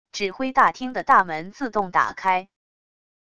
指挥大厅的大门自动打开wav音频